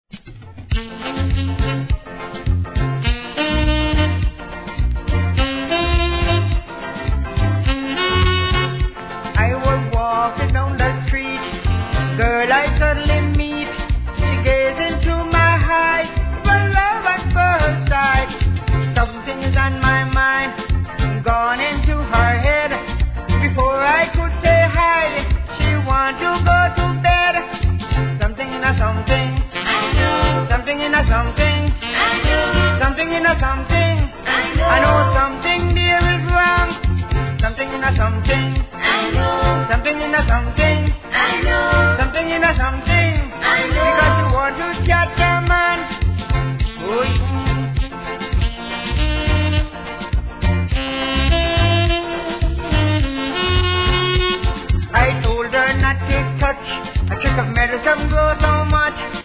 REGGAE
いなたいSAX & コーラスがイイ味のソカ・カリプソ!!